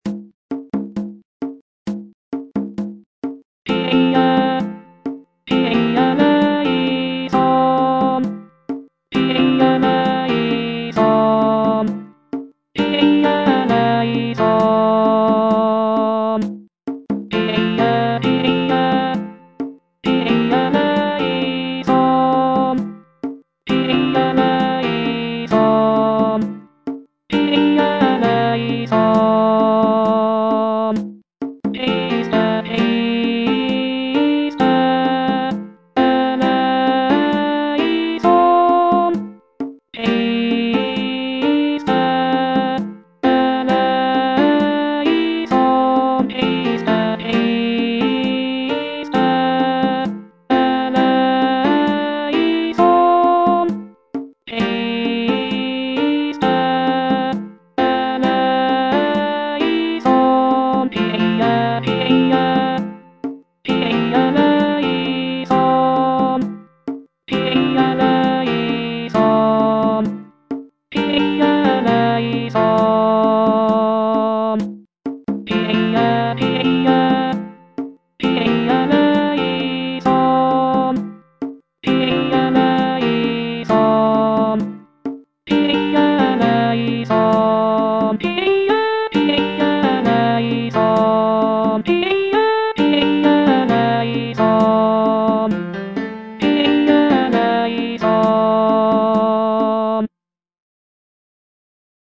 Alto (Voice with metronome) Ads stop